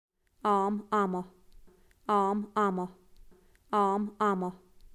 The recordings were gained from native speakers from the individual areas as part of the project Samples of Spoken Irish.
[listen] ɑ: